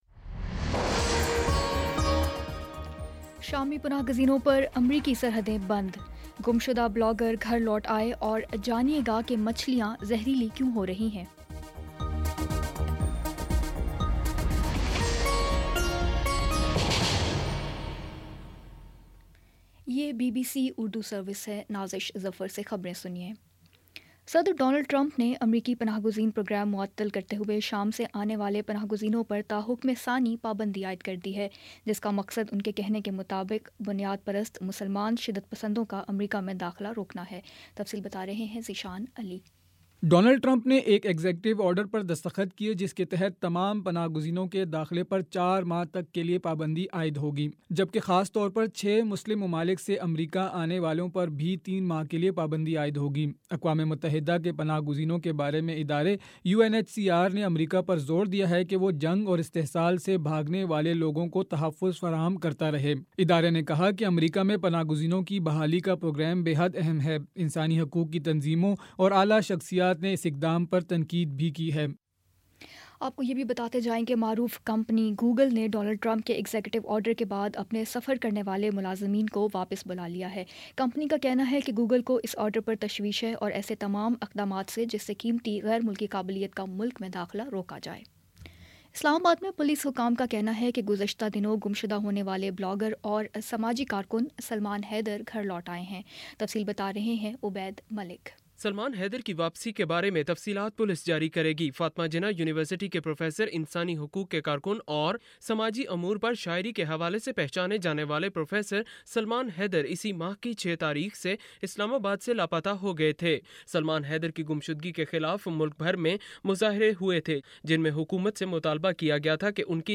جنوری 28 : شام پانچ بجے کا نیوز بُلیٹن